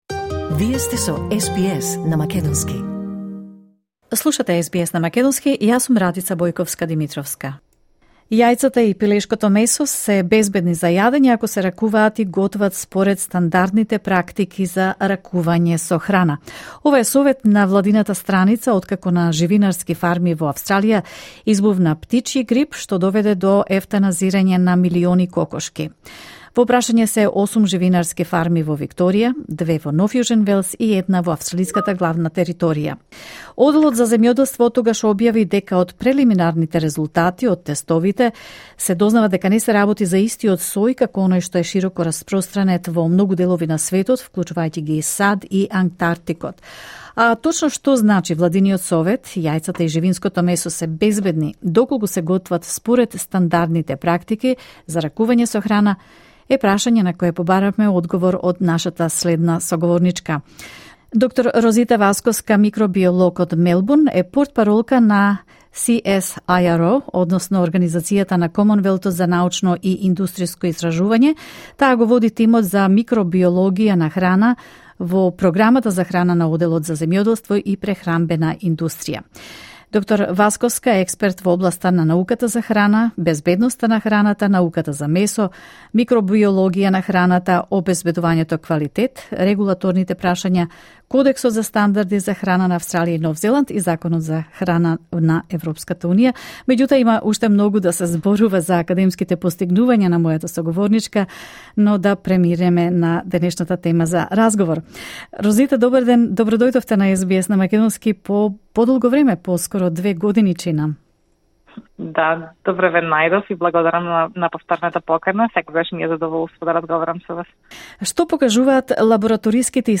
Слушнете подетално во разговорот за СБС на македонски.